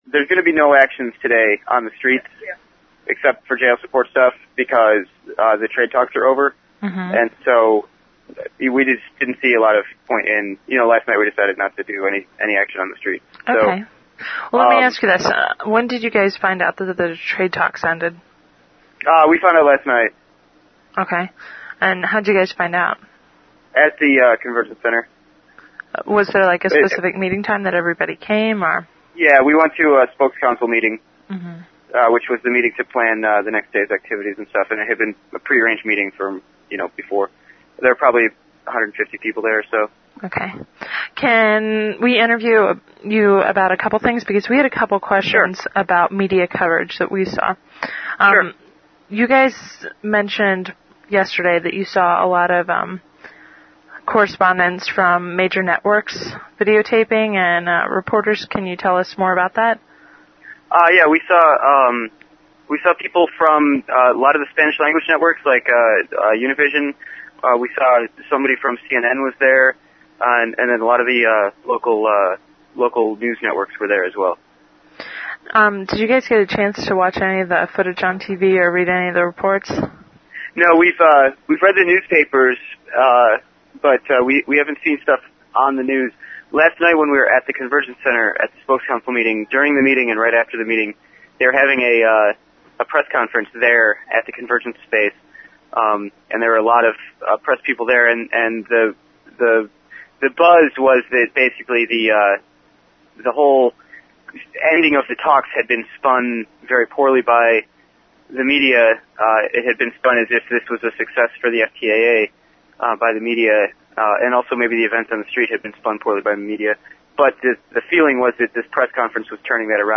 Miami Anti-FTAA Audio Report: Summary Of Thursday's Events And Plans For Friday